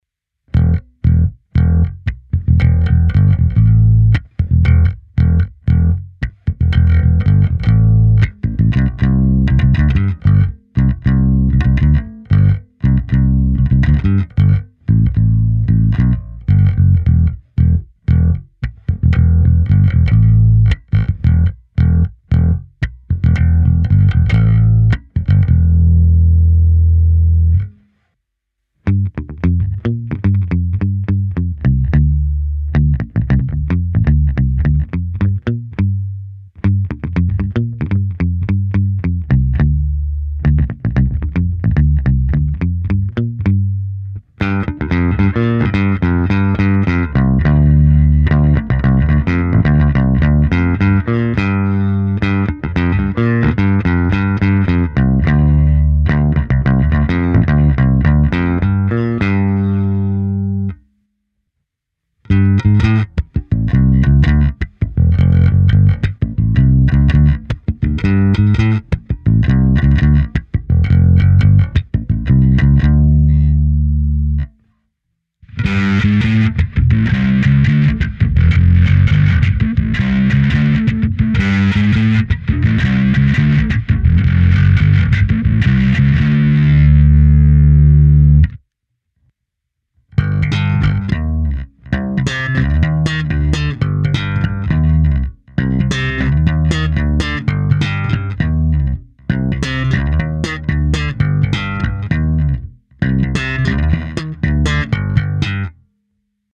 Nahrávka na oba snímače se simulací aparátu, kde bylo použita i hra trsátkem, se zkreslením, a dokonce i hra slapem.